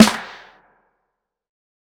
HFMSnare9.wav